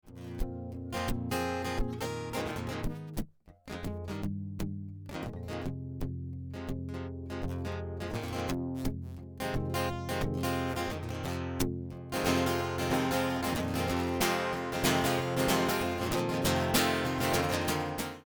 New to recording -- What's making this acoustic-electric sound so bad?
My friend has a Taylor acoustic/electric guitar that has a line into a Focusrite Saffire 6 USB (I know, this is not preferable to miking). His vocals are running through the XLR on the Saffire simultaneously, which is then plugged into Logic and recording them both onto separate tracks. But the guitar keeps sounding really tinny and poppy, even though the levels don't spike during recording.